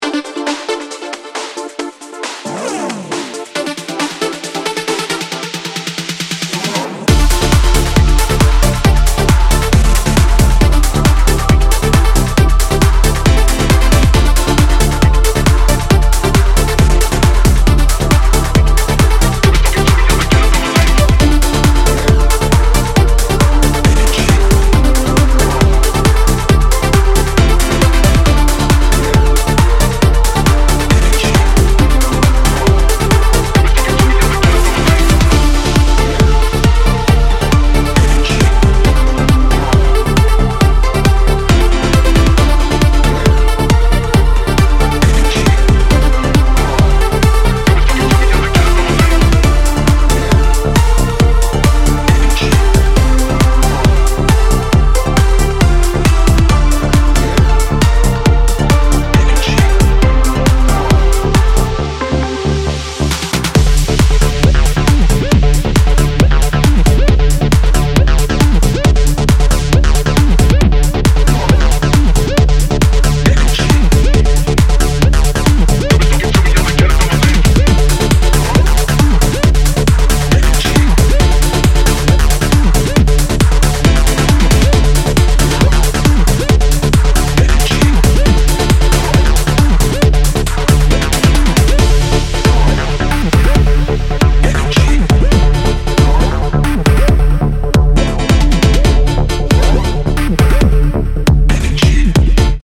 full throttle vitality and booming grooves
90’s sample-filled techno